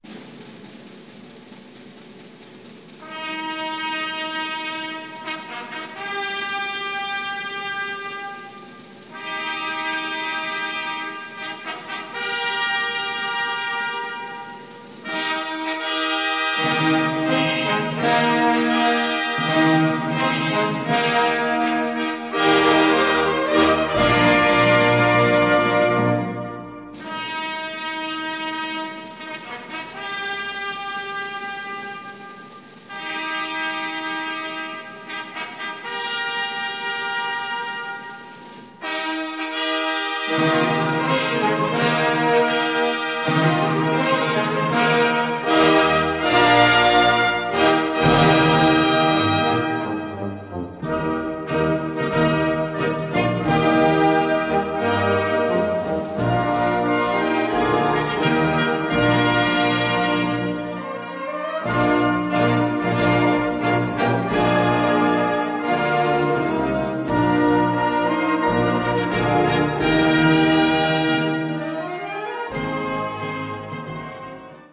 Gattung: Eröffnungsstück
Besetzung: Blasorchester